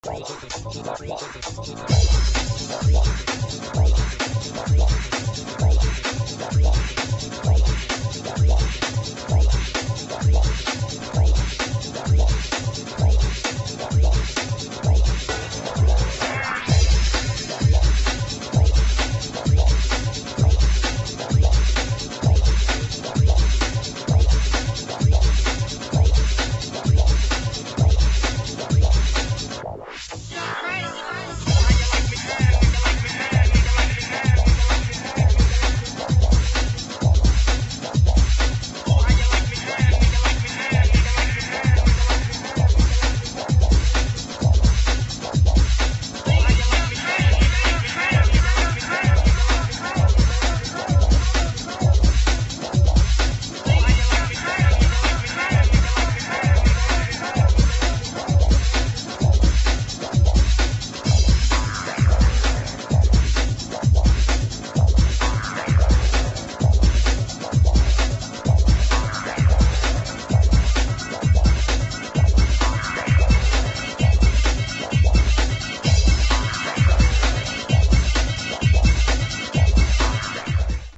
[ BREAKS ]